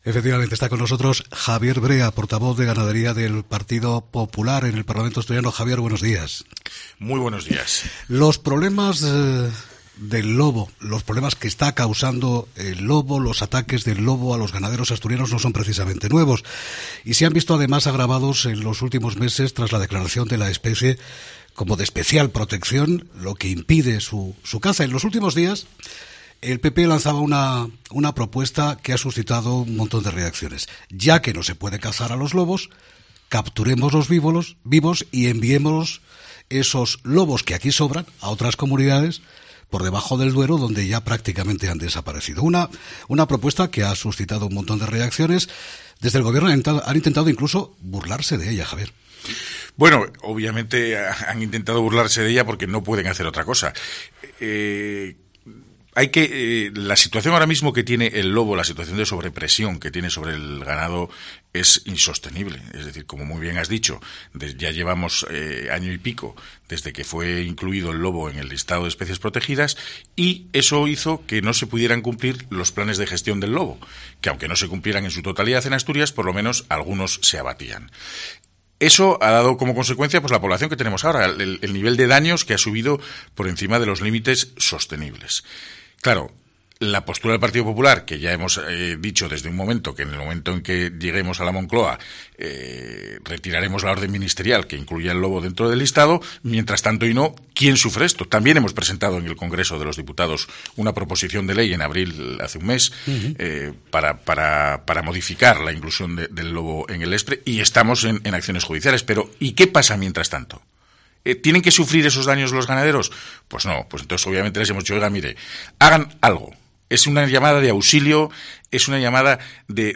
Entrevista a Javier Brea, portavoz de ganadería del PP asturiano
Para hablar de la situación del lobo y aclarar los objetivos de su propuesta, este miércoles ha estado en COPE Asturias el portavoz de ganadería del PP asturiano, Javier Brea Pastor.